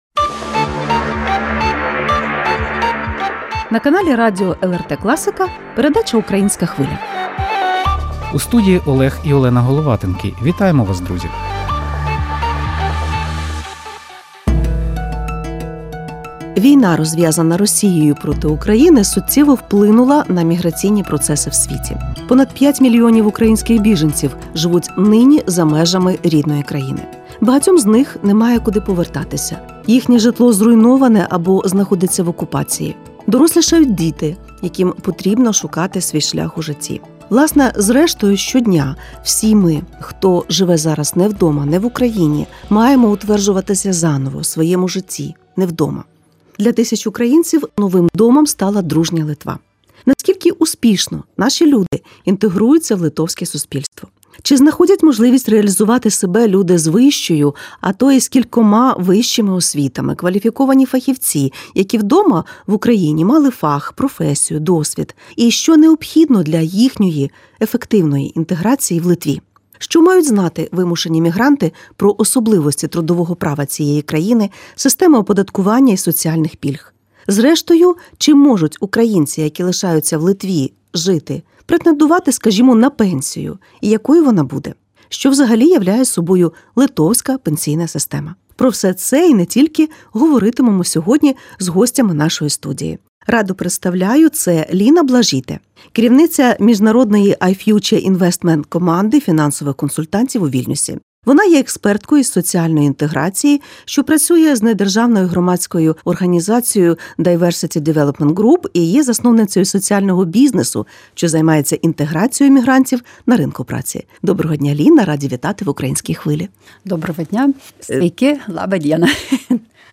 - про це говоримо з експертами із соціальної інтеграції та фінансовими консультантами.